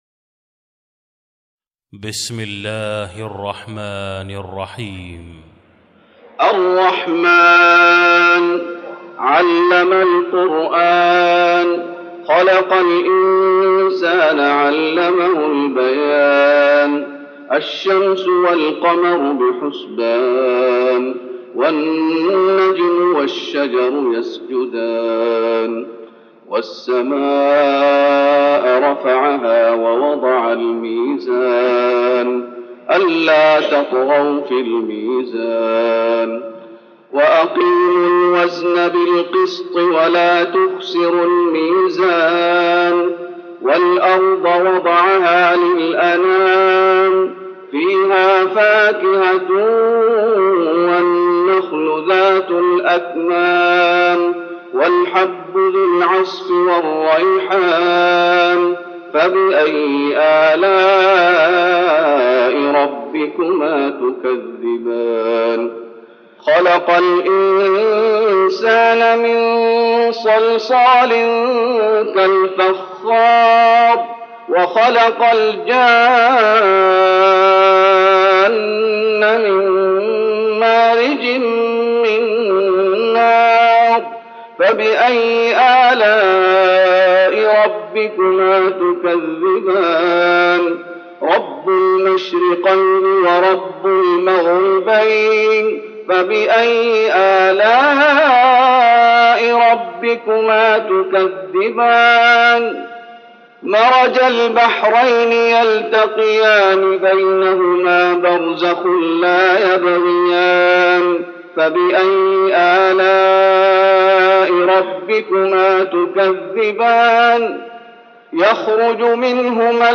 تراويح رمضان 1413هـ من سورة الرحمن Taraweeh Ramadan 1413H from Surah Ar-Rahmaan > تراويح الشيخ محمد أيوب بالنبوي 1413 🕌 > التراويح - تلاوات الحرمين